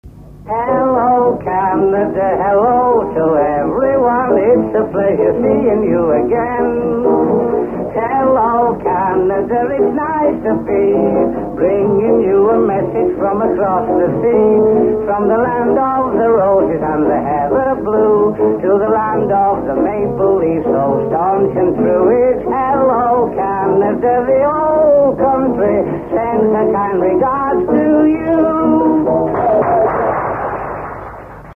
from his 1947 tour.